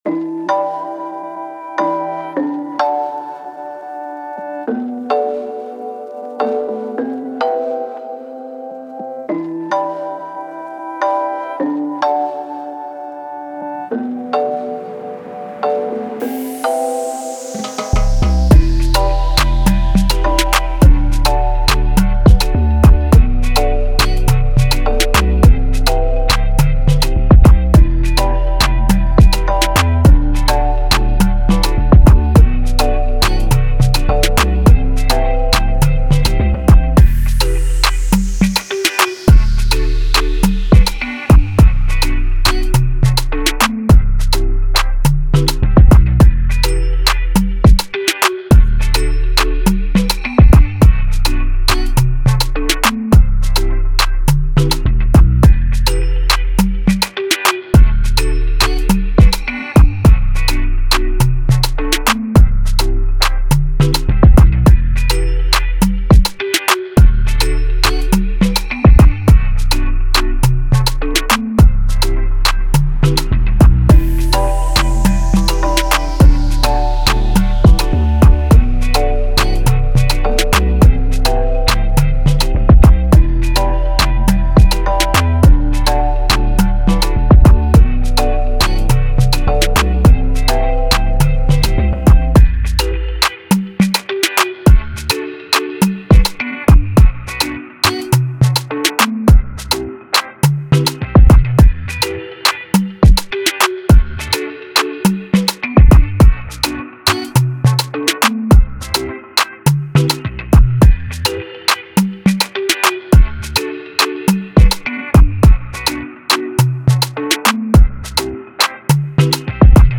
Reggae, Afrobeat, Dance
G min